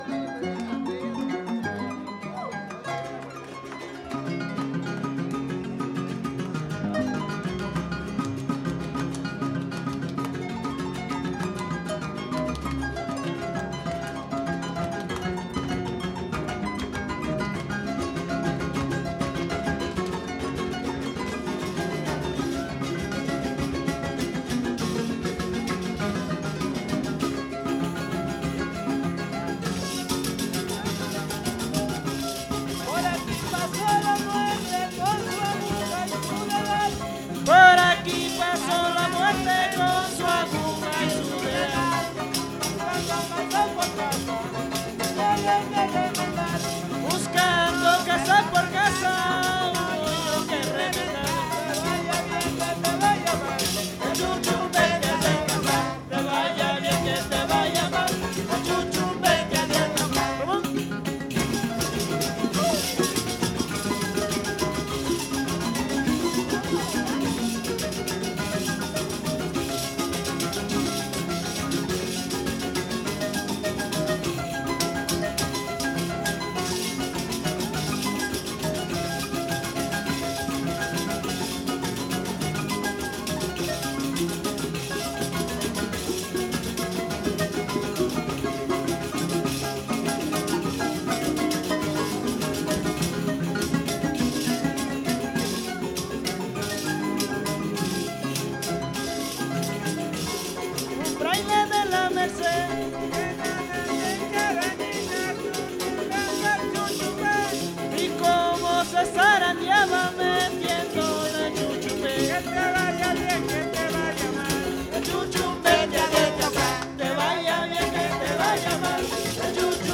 Canción tradicional Son jarocho
Sexto Encuentro de Etnomusicología. Fandango de clausura